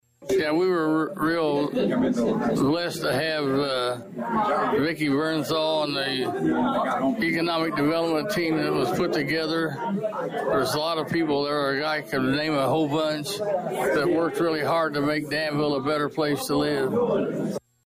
The occasion was an early birthday reception for Jones, who was Danville’s mayor from 1987 until 2003.
The reception honoring Jones took place prior to the regular city council meeting.